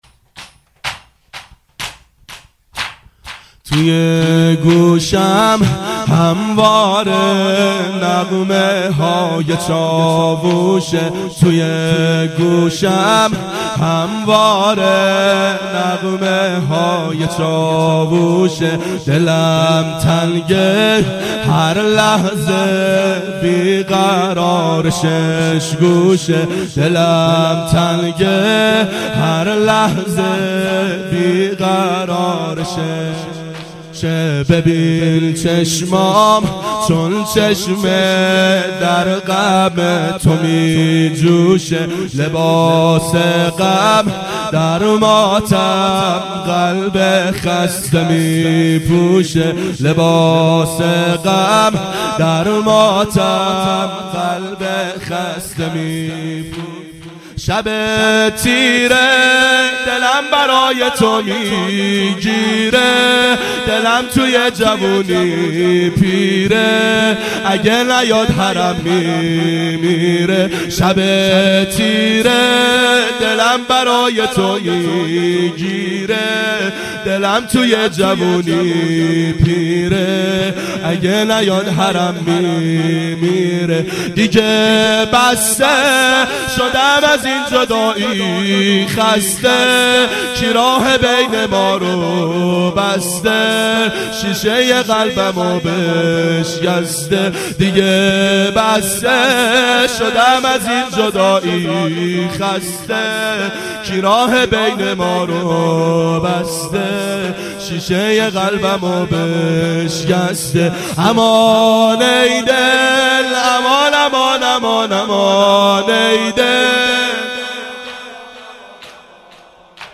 ظهر اربعین سال 1389 محفل شیفتگان حضرت رقیه سلام الله علیها